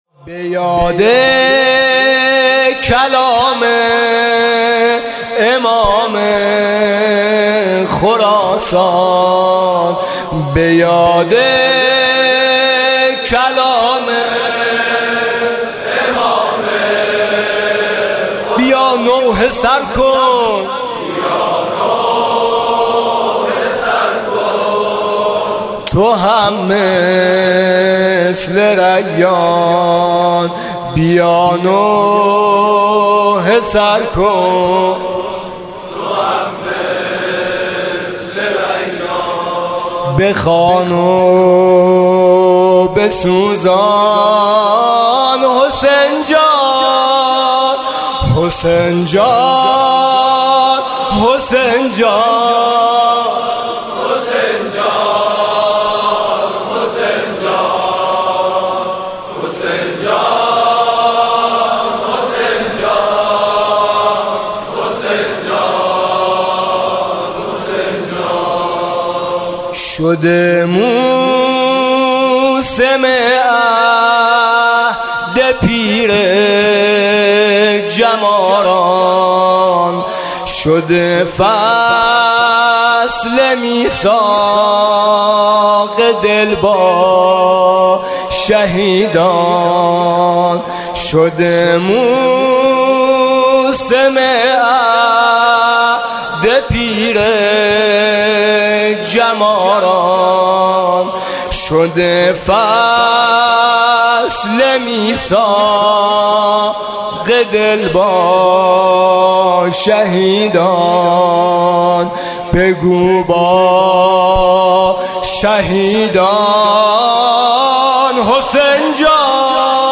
محرم 95